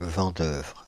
Vendeuvre (French pronunciation: [vɑ̃dœvʁ]
Fr-Vendeuvre.ogg.mp3